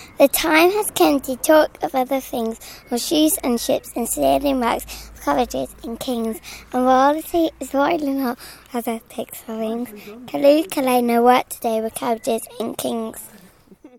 At Moulton Seas Ends Got Talent in June